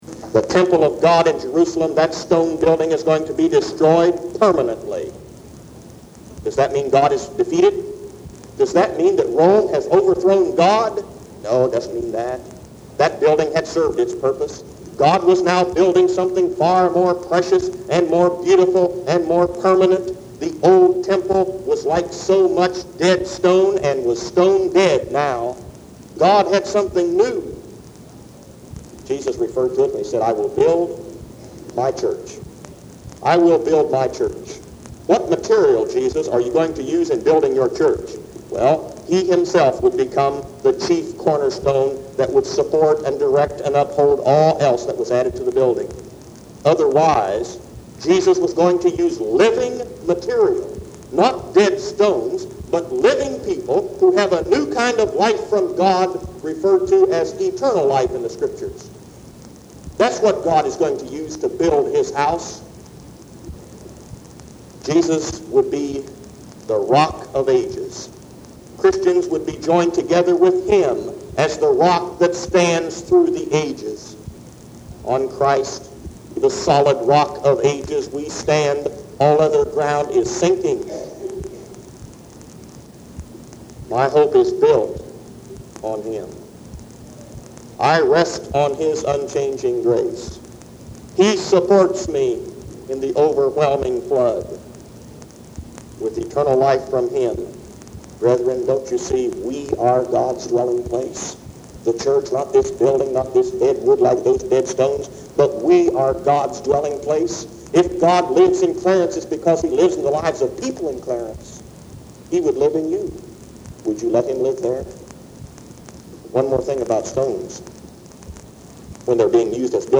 The audio quality is diminished due to the older cassette, and the sermon cuts off at the end, but most of it is still here!